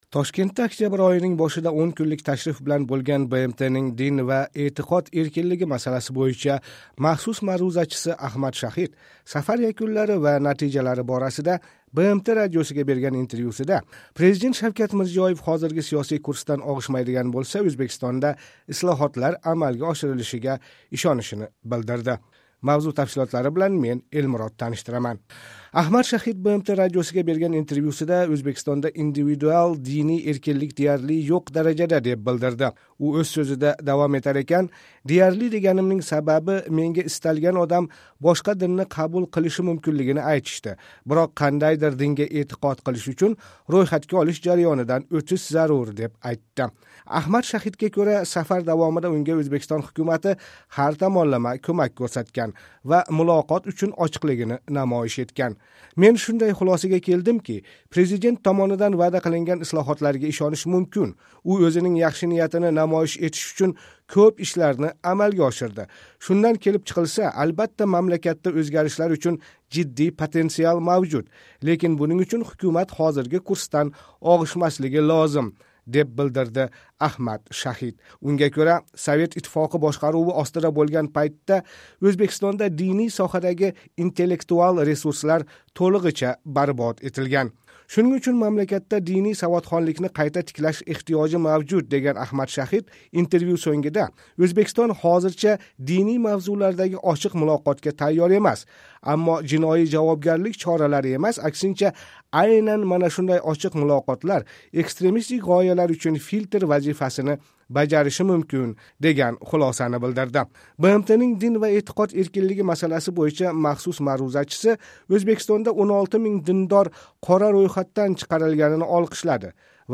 Тошкентда октябрь ойининг бошида 10 кунлик ташриф билан бўлган БМТнинг дин ва эътиқод эркинлиги масаласи бўйича махсус маърузачиси Аҳмад Шаҳид сафар якунлари ва натижалари борасида БМТ радиосига берган интервьюсида президент Шавкат Мирзиёев ҳозирги сиёсий курсдан оғишмайдиган бўлса, Ўзбекистонда ислоҳотлар амалга оширилишига ишонишини билдирди.